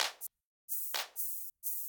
clap seul.wav